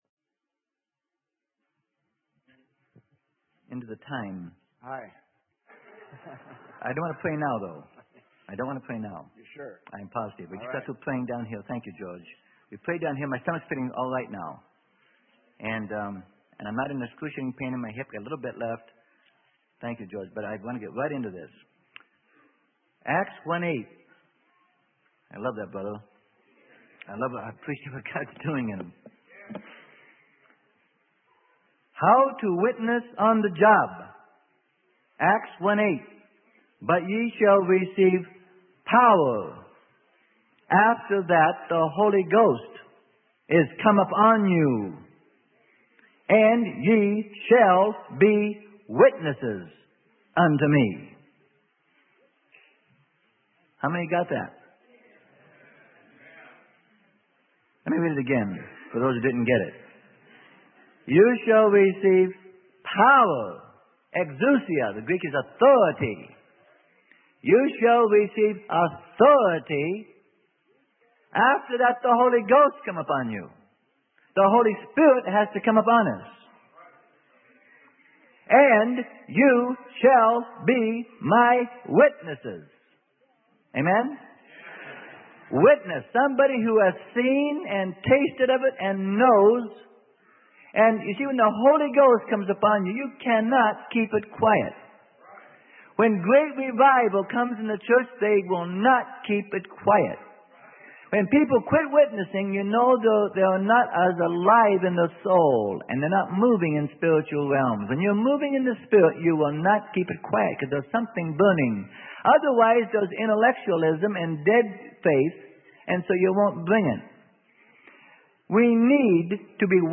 Sermon: How to Witness on the Job - Part 1 - Freely Given Online Library